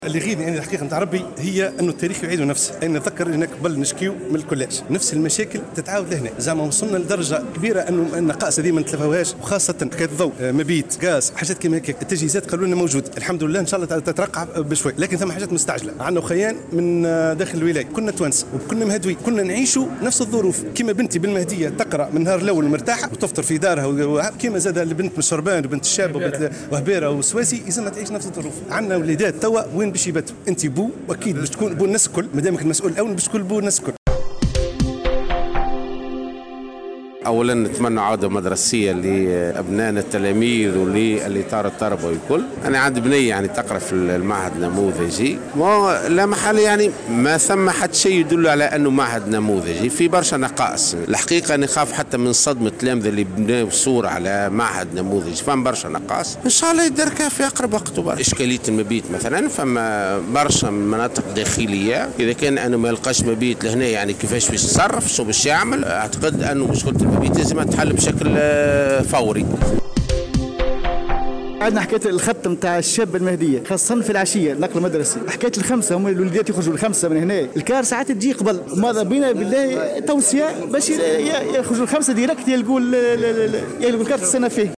وندّد بالمناسبة أولياء التلاميذ الحاضرين أمام المعهد ببعض النقائص أهمها المبيت المدرسي، والتي اعتبروها من أساسيات نجاح هذه العودة ويجب تداركها، وفق قولهم في تصريح ل “ام اف ام”.